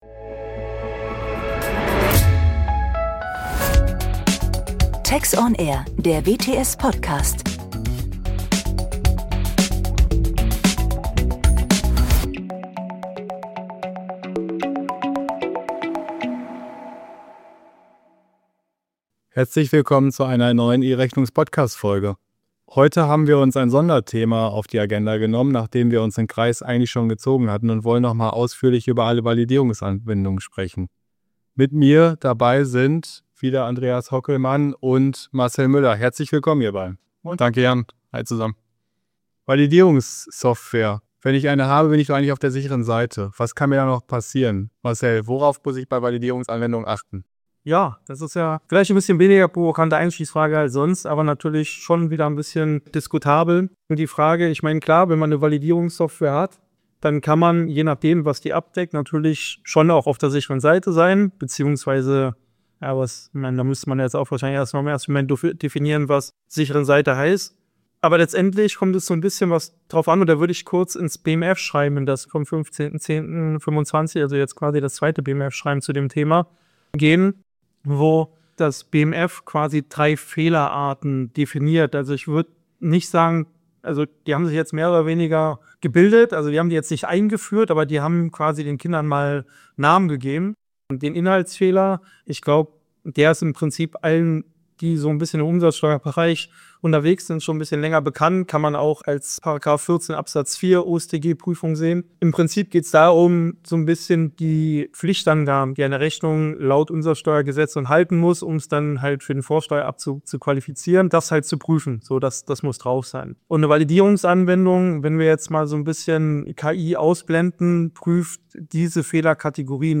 Die Podcast-Reihe „Einfach E-Rechnung“ beleuchtet im Rahmen von kurzweiligen Expertengesprächen neben den aktuellen rechtlichen Entwicklungen und Rahmenbedingungen in Deutschland auch viele technische und prozessuale Fragestellungen im Rechnungseingangs- und Rechnungsausgangsprozess.